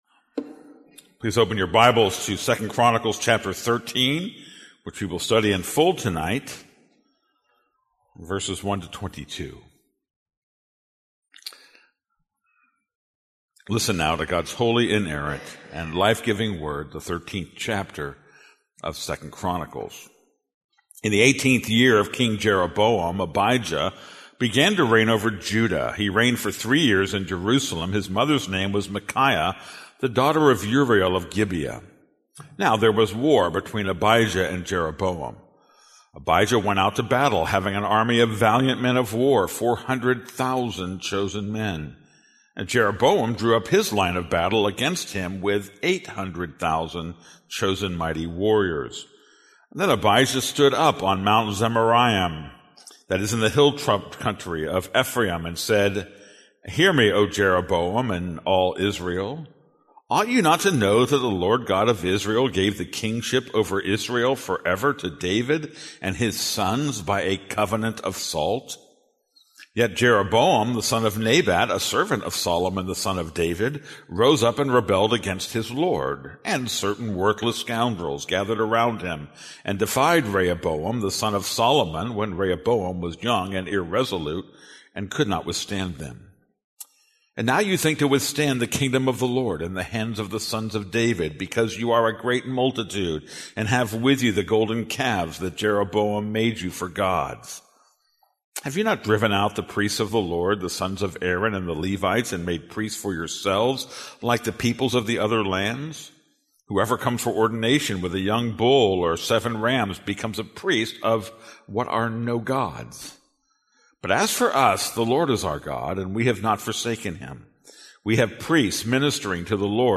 This is a sermon on 2 Chronicles 13:1-22.